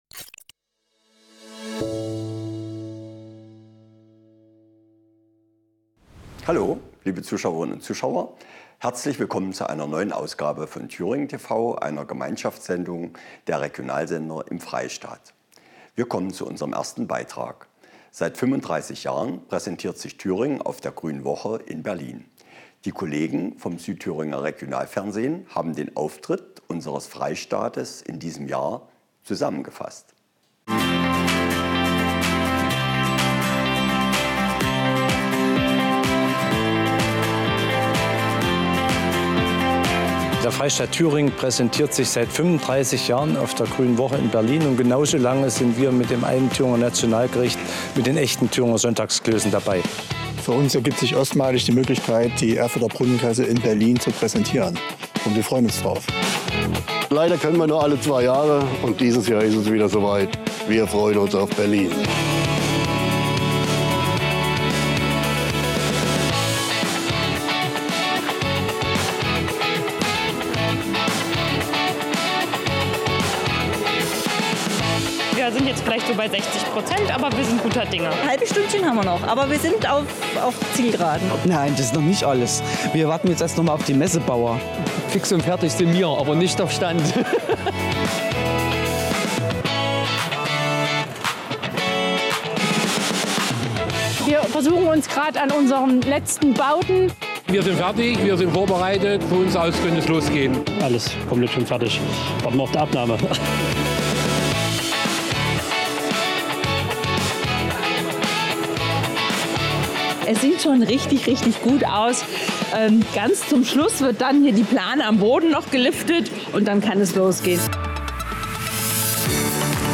Erfurt, 17.06.2018/ Menschen aus verschiedenen Nationalit�ten trafen sich im Wirgarten in Erfurt, um das Zuckerfest zu feiern. Das Zuckerfest, auch Eid genannt, ist ein traditionell muslimisches Fest, das am Ende des Ramadans gefeiert wird.
Es gab Live-Musik, ein internationales Buffet, den ber�hmten Dabkeh-Tanz und vieles mehr.